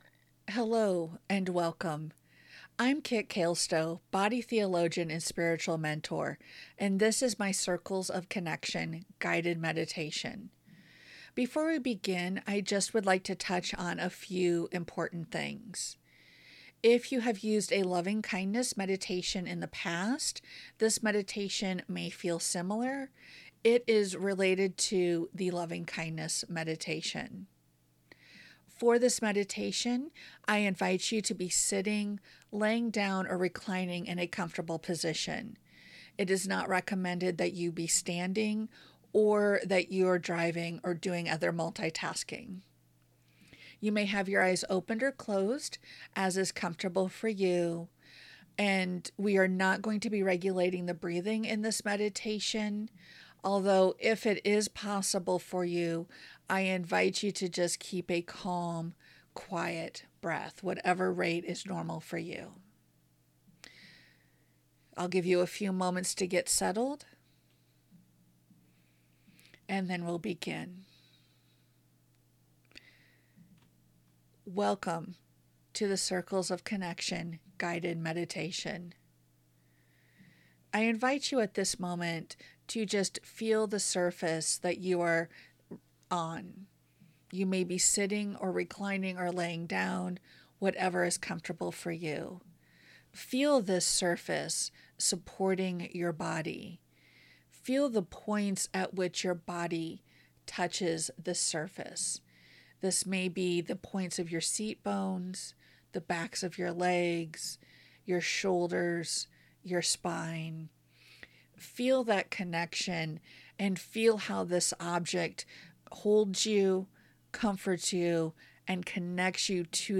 Circle of Connection Guided Meditation - Feathermane Soul
Circles of Connection Guided Meditation.mp3